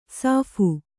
♪ sāphu